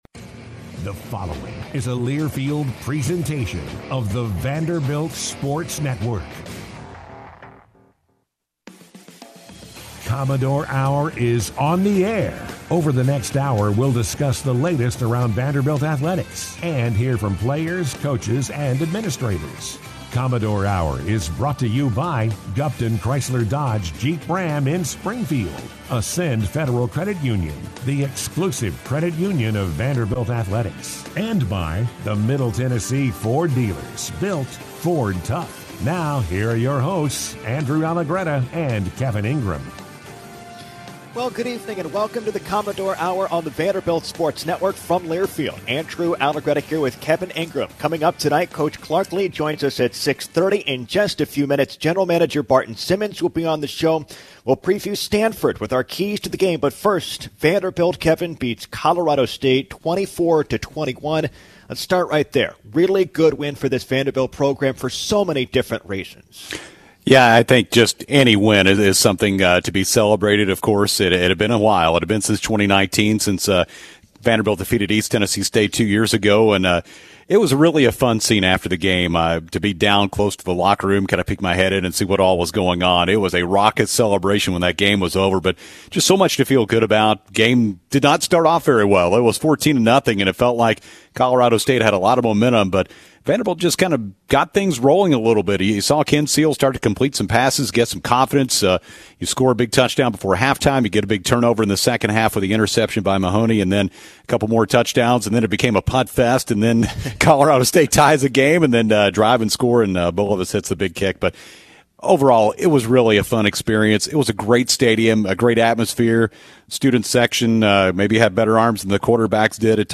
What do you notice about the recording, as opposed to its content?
host the Commodore Hour Monday nights from 6-7 on ESPN 94.9